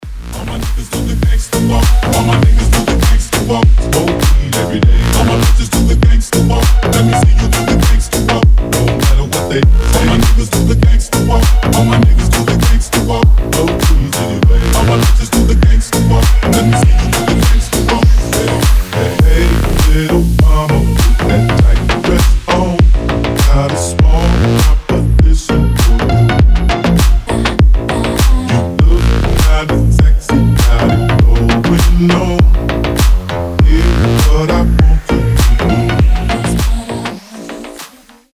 • Качество: 320, Stereo
качающие
G-House
Gangsta